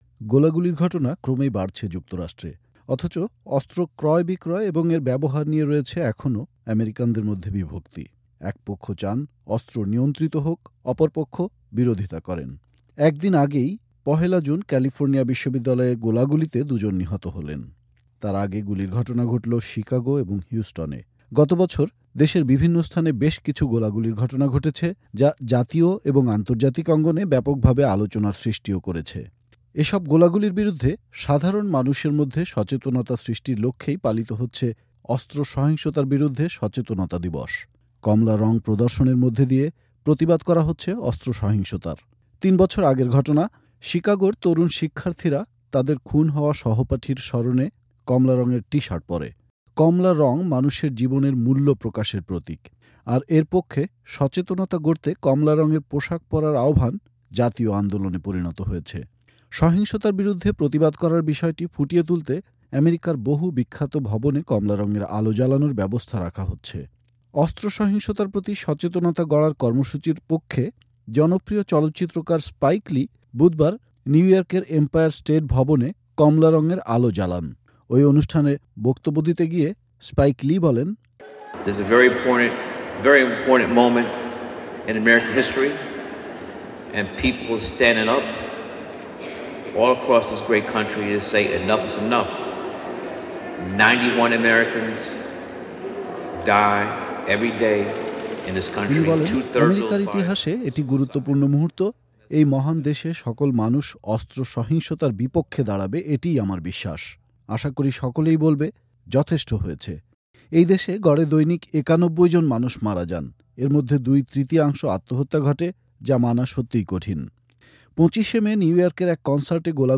রিপোর্ট